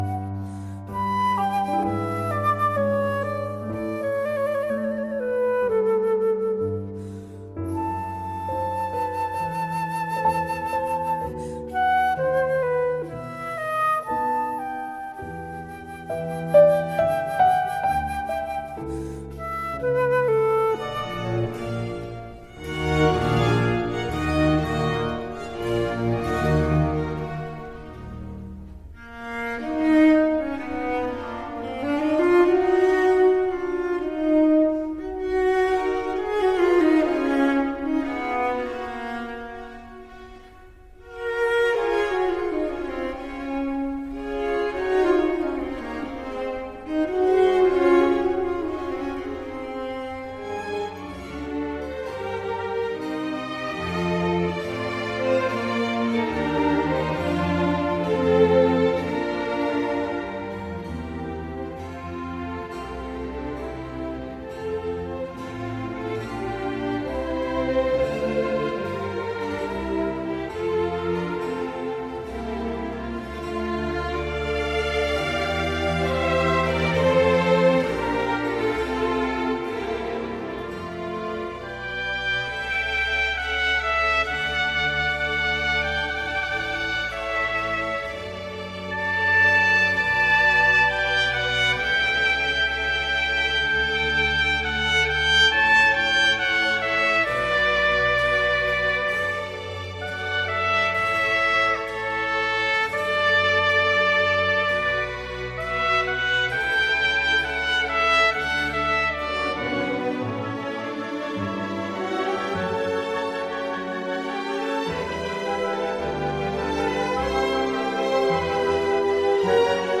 Relaxing & Calming Classical
Relaxing Classical Music